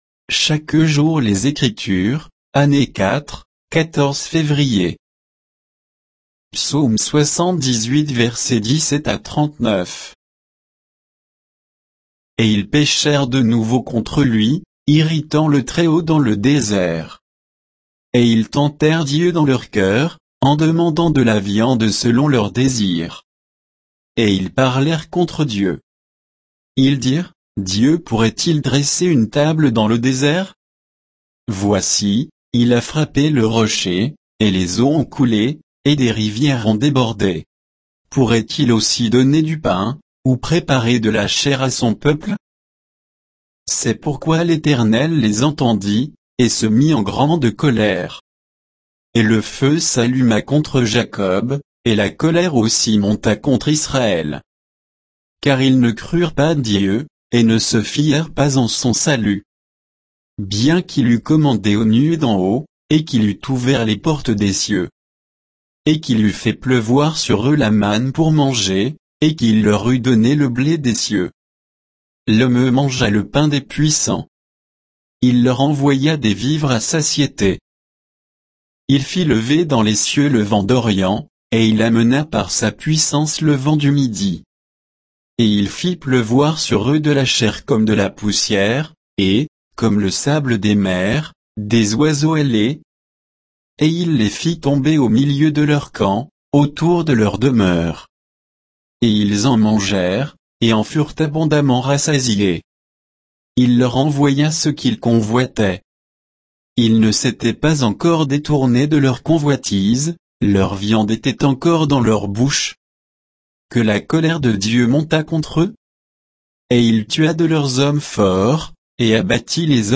Méditation quoditienne de Chaque jour les Écritures sur Psaume 78